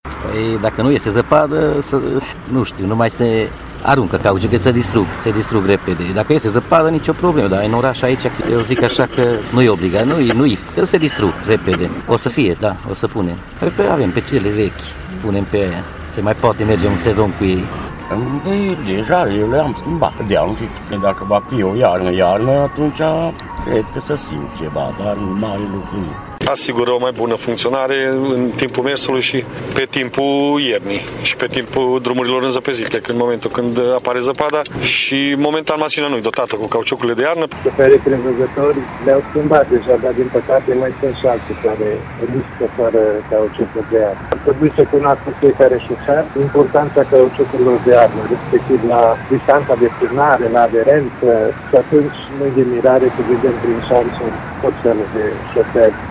Șoferii au înțeles importanța anvelopelor de iarnă, dar mulți speră ca iarna să nu țină prea mult și să nu fie nevoiți să facă investiții în plus: